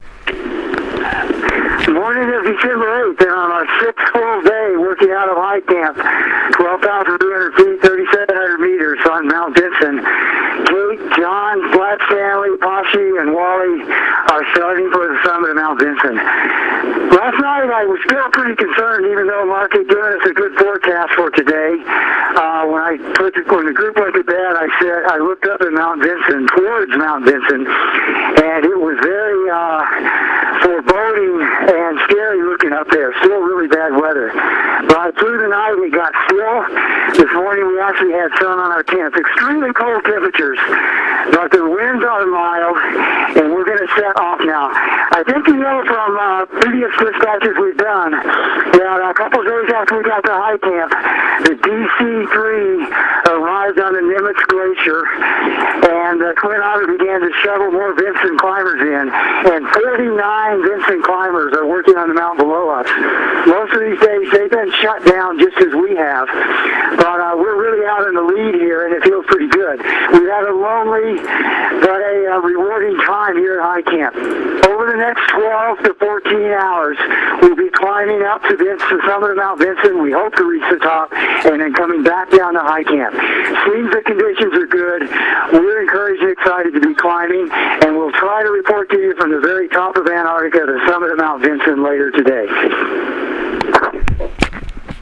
Vinson Expedition Dispatch
Leaving high camp for the summit